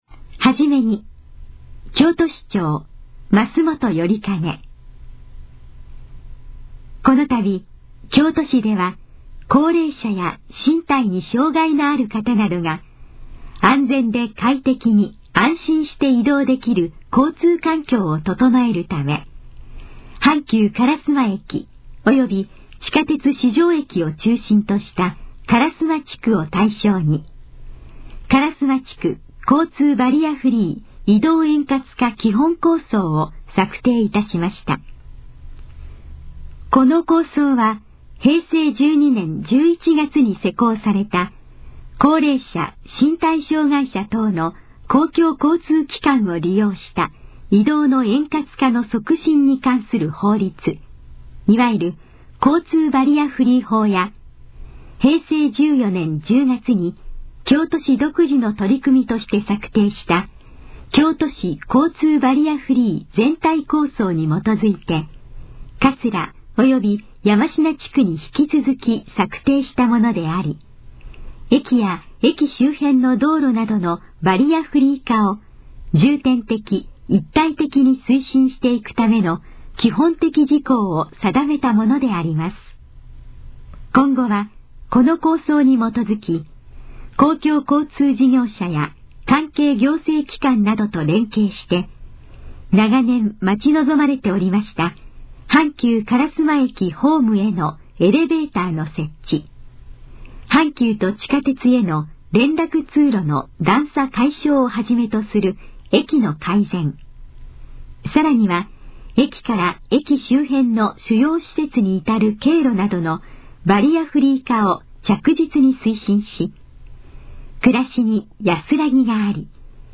このページの要約を音声で読み上げます。
ナレーション再生 約324KB